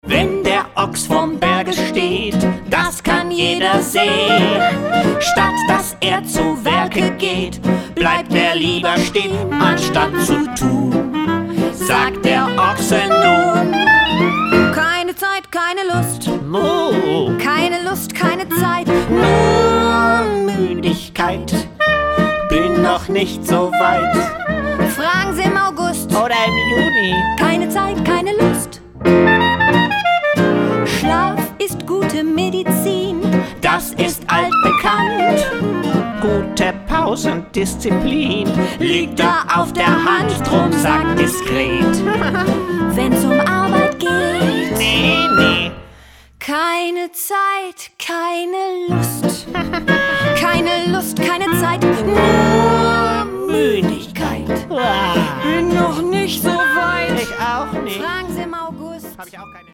Musical für Kinder